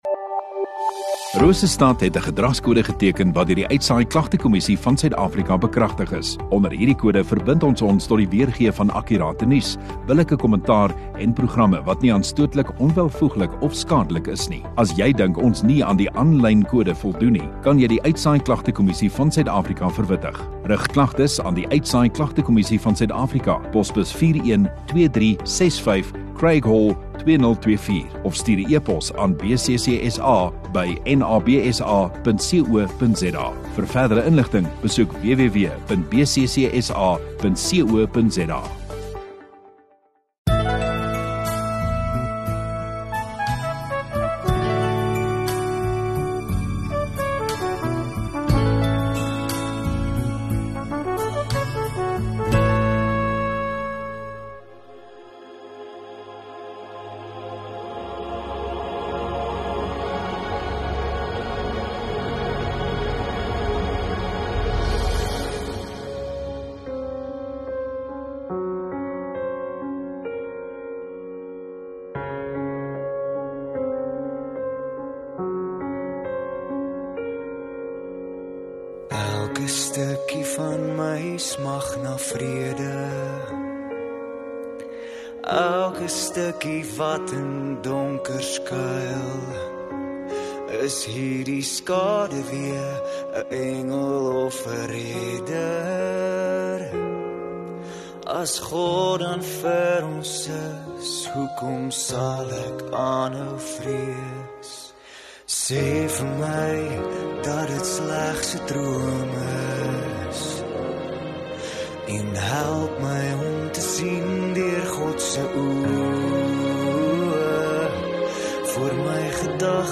24 Aug Sondagoggend Erediens